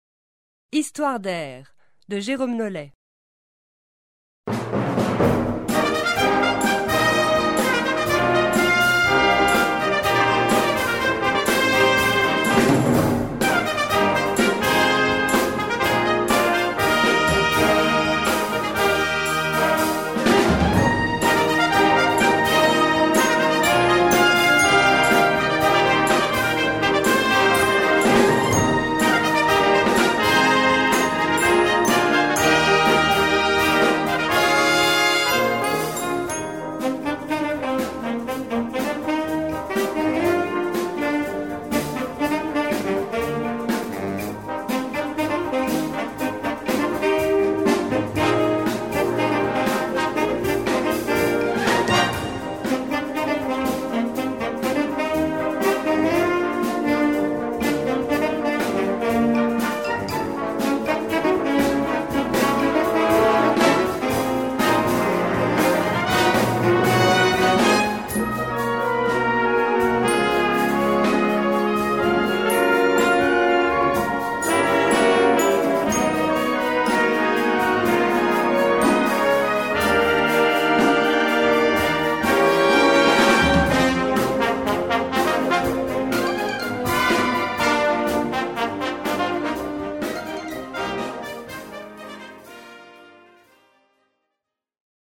Répertoire pour Harmonie/fanfare